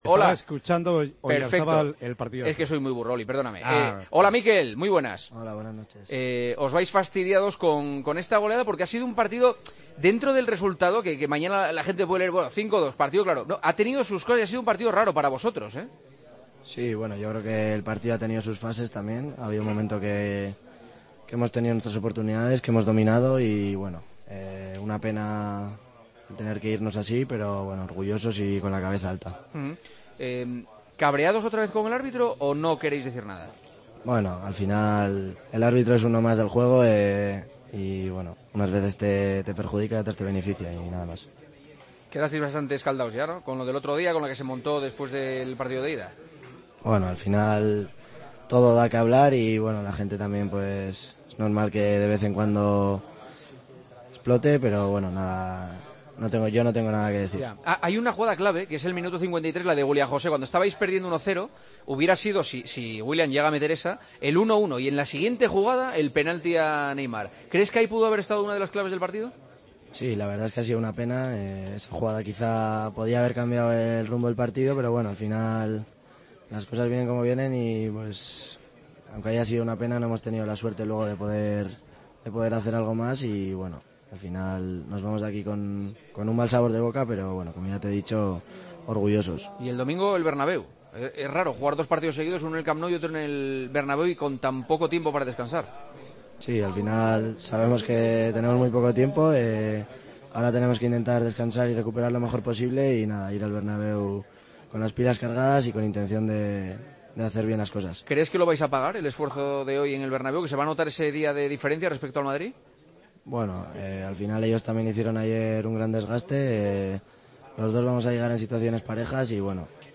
Oyarzabal, en El Partidazo de COPE: "Nos vamos con mal sabor de boca, pero orgullosos"